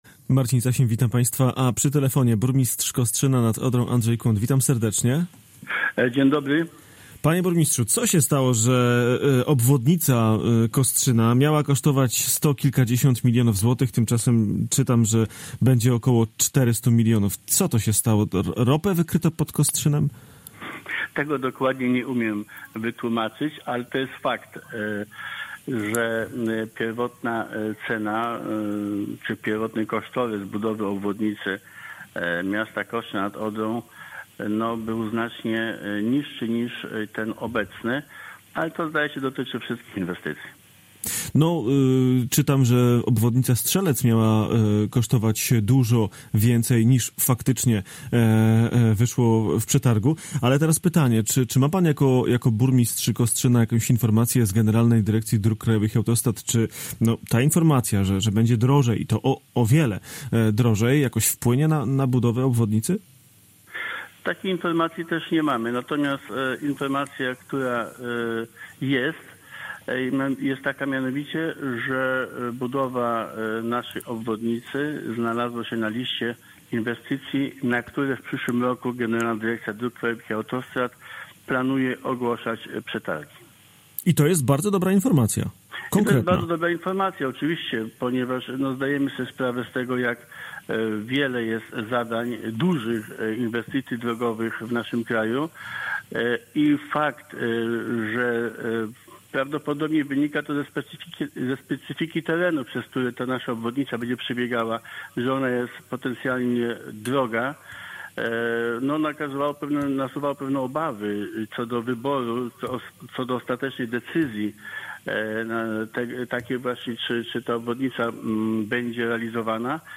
Z burmistrzem Kostrzyna nad Odrą